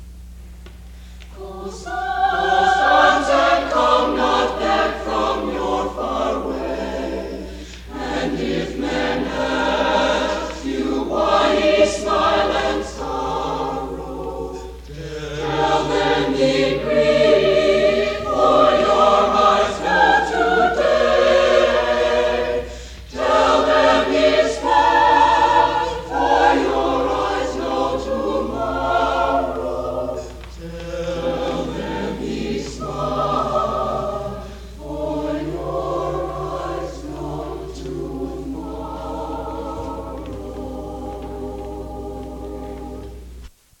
Highland Park, MI, High School Concert Choirs, 1954-1969
CD for the 1966 and 1967 Spring Concerts